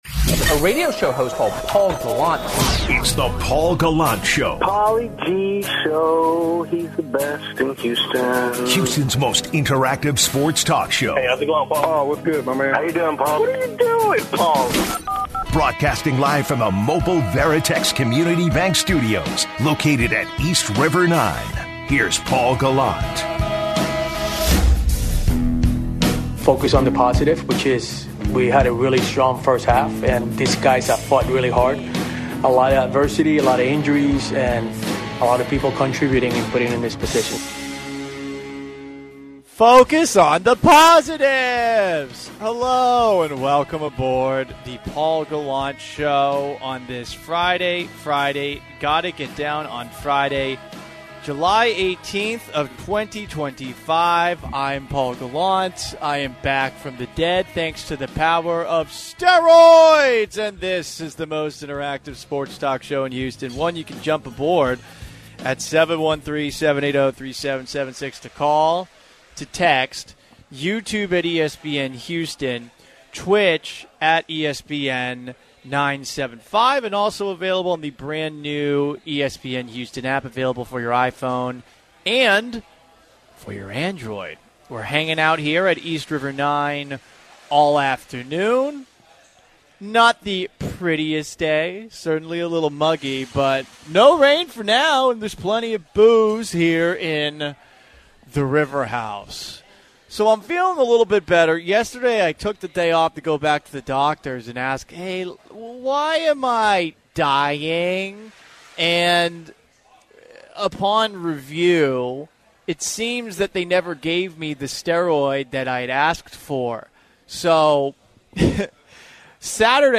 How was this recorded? LIVE from East River 9!